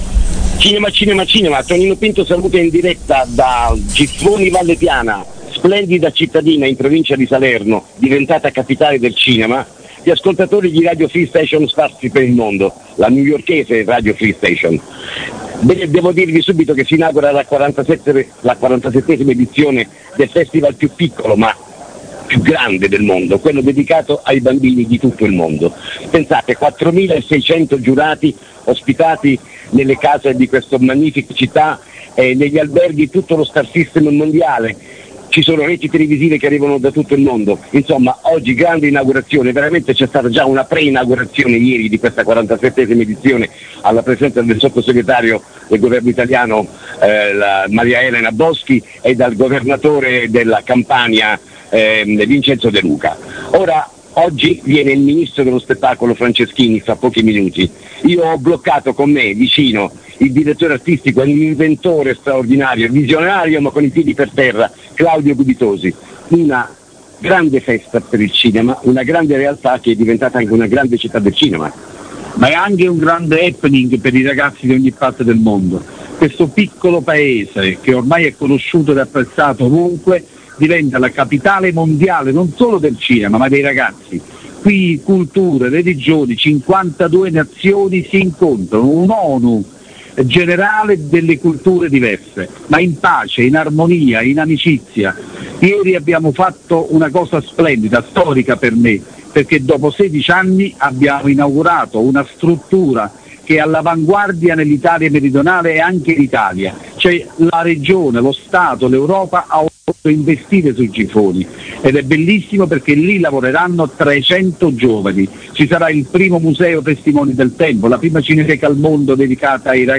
musica / PODCAST RADIOFREESTATION / A...PODCAST INTERVISTE / Ospiti 2017 / FESTIVAL DI GIFFONI /